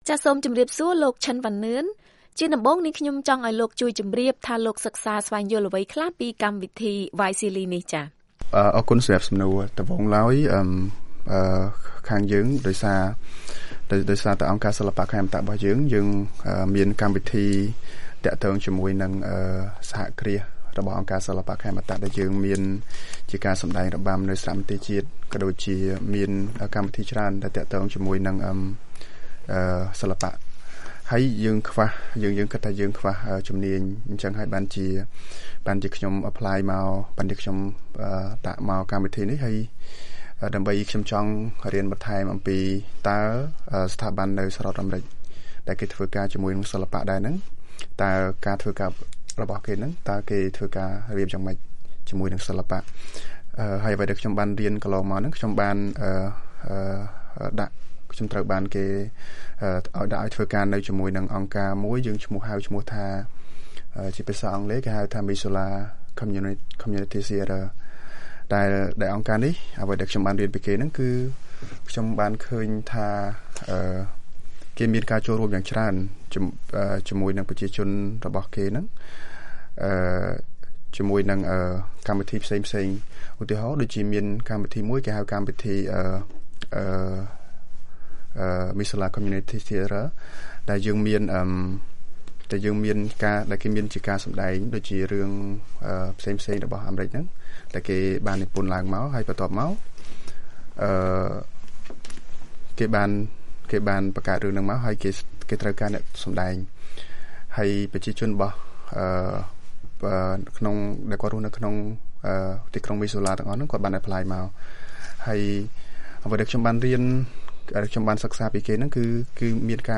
បទសម្ភាសន៍ VOA៖ សារៈសំខាន់នៃការរួមចំណែករបស់សិល្បៈទៅក្នុងសង្គម